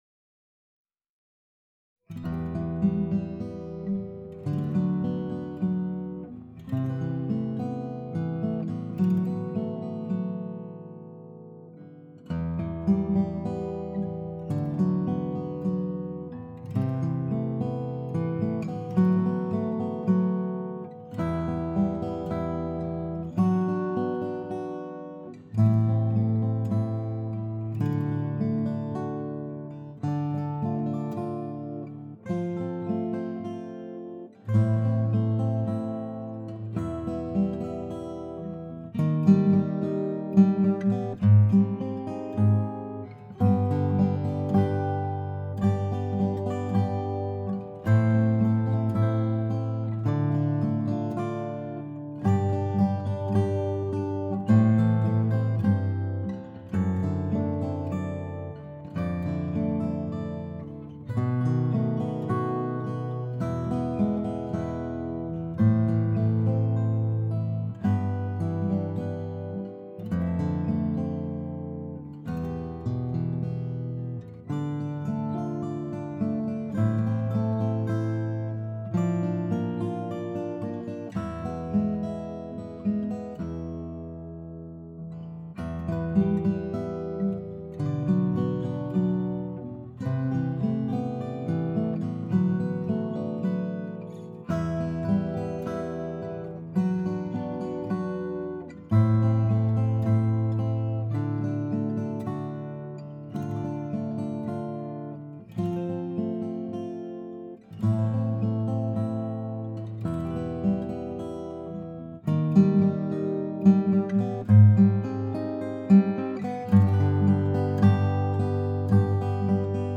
Every acoustic song of mine has two or more tracks of guitar parts. For “Misunderstood” I decided to add some strumming, which is very new.
Misunderstood Guitar Mix
misunderstood-guitar-mix-7-15-18.mp3